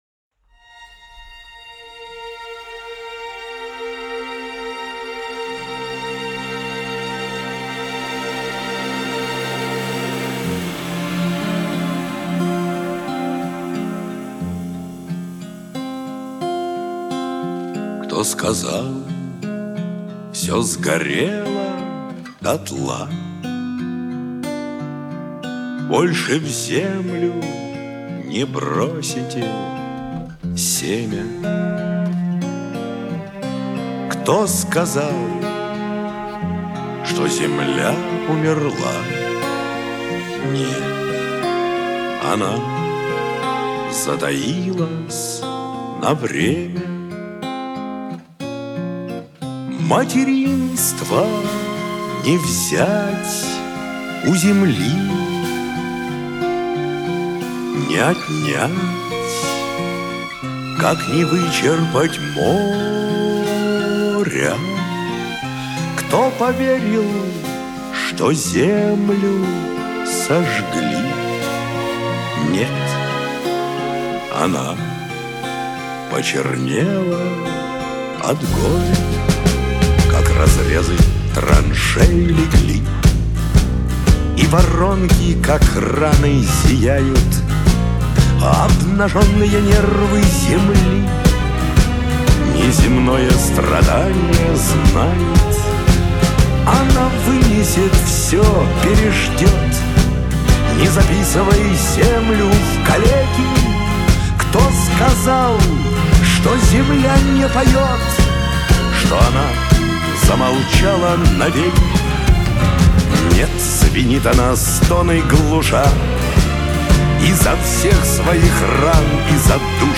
Шансон , грусть , эстрада , Лирика